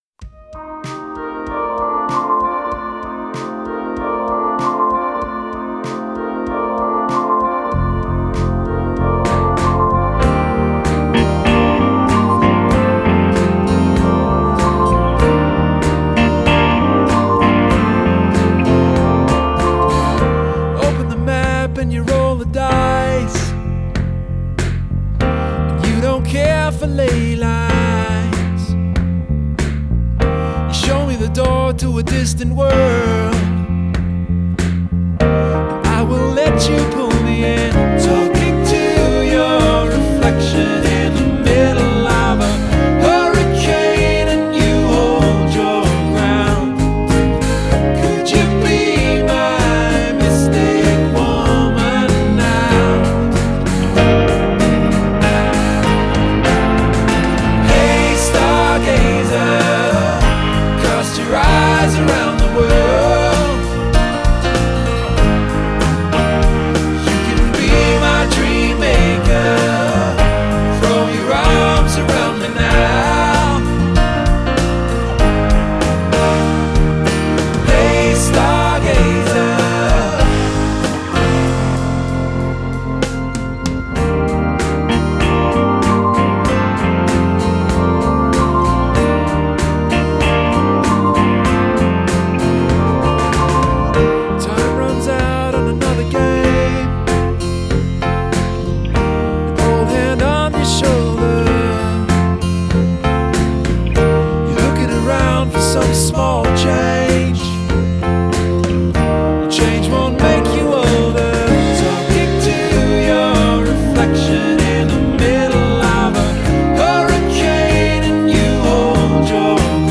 melodic, rootsy, contemporary pop music